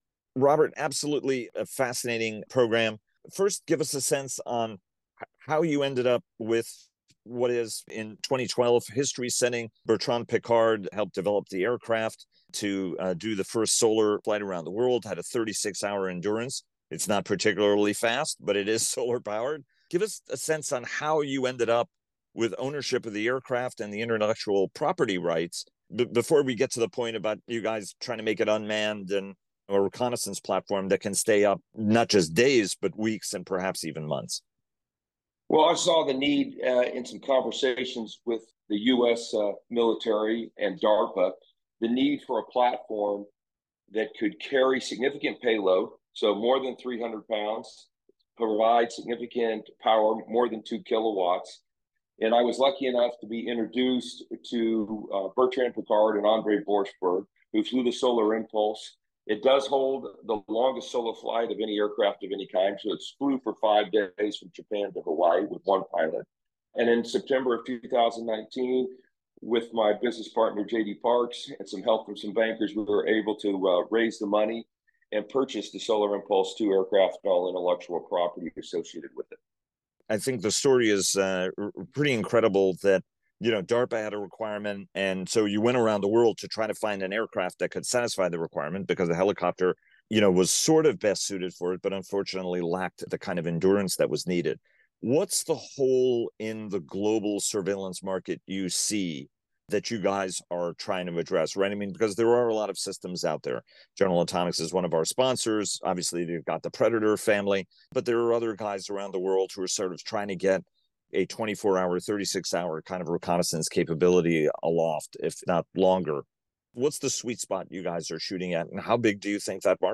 Defense & Aerospace Report interview